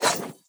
Cybernetic Technology Affirmation 2.wav